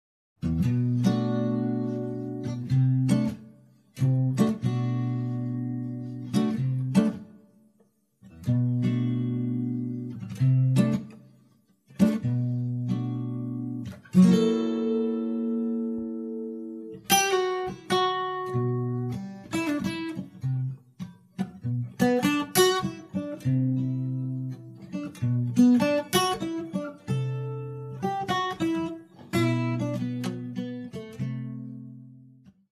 Akustische Gitarren
Alles pur, keine Overdubs.